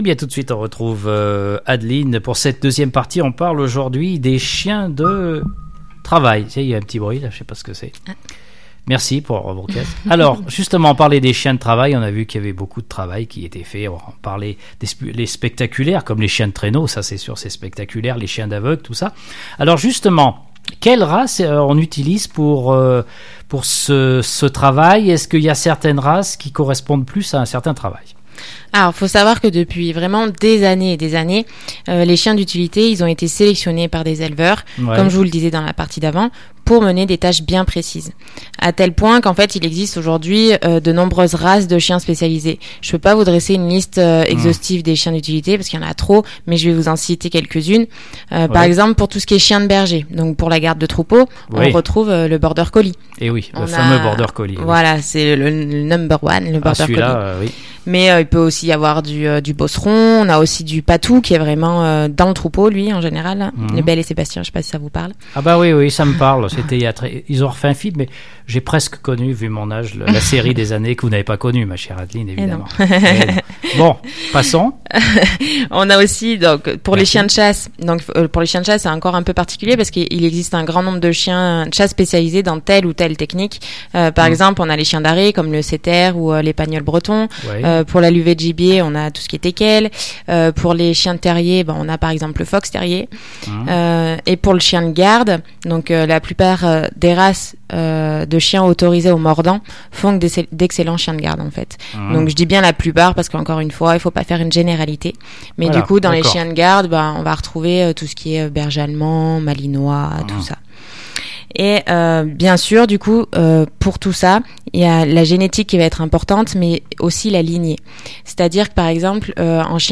1 juin 2020   1 - Vos interviews, 2 - Infos en Bref, 3 - Santé et social   No comments